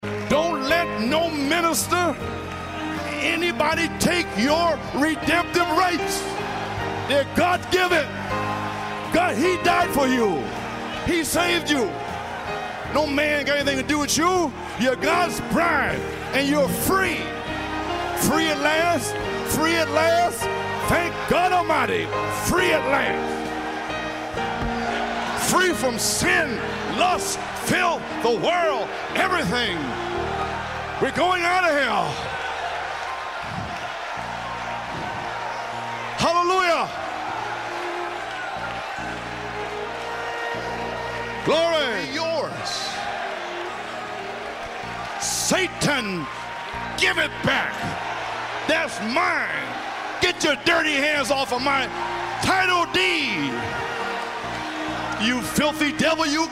UNE PRÉDICATION PUISSANTE AVEC LE sound effects free download